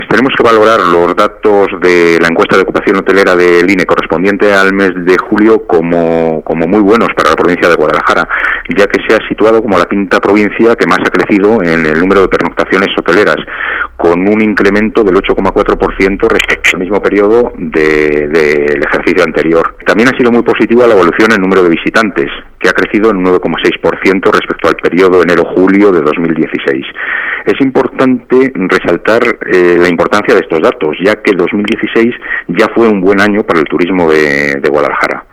El director provincial de Economía, Empresas y Empleo de Guadalajara, Santiago Baeza, habla de los datos de viajeros y pernoctaciones en alojamientos hoteleros en la provincia durante lo que va de año.